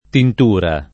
tintura [ tint 2 ra ] s. f.